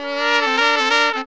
HORN RIFF 29.wav